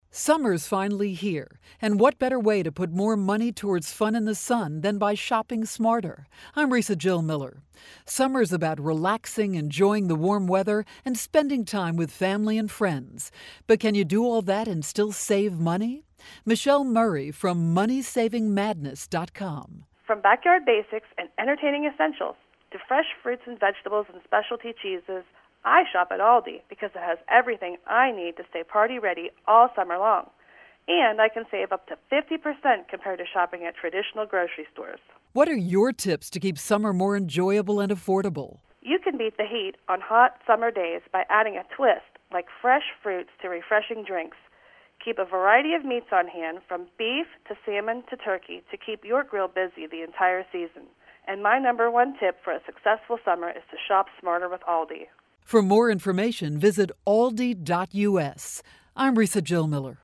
June 18, 2013Posted in: Audio News Release